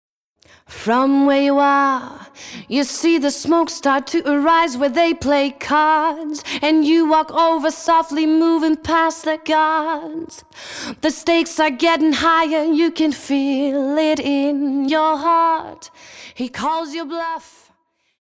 Мы предлагаем Вам отделить голос от музыки на уровне акустического спектра.
Только голос